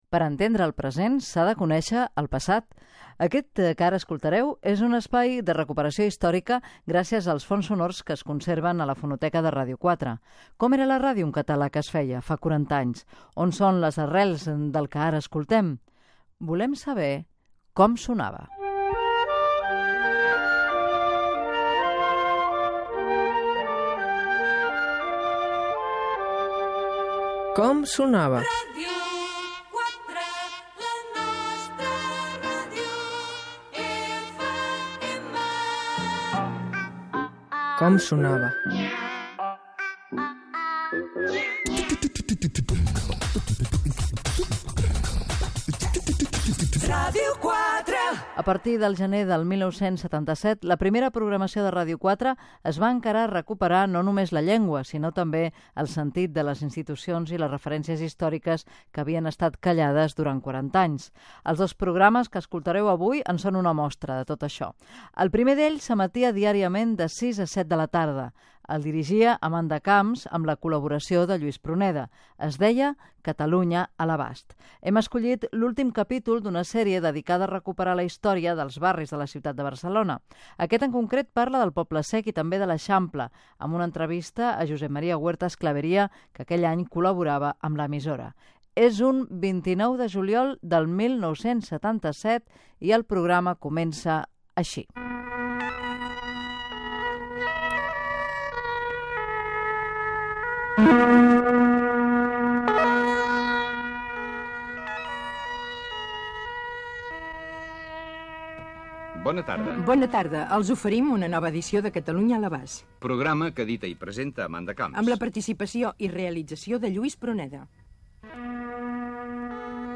Primera edició del programa dedicat als 40 anys de Ràdio 4. Presentació, careta, una edició del programa "Catalunya a l'abast" del mes de juliol de l'any 1977
Entreteniment